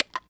kits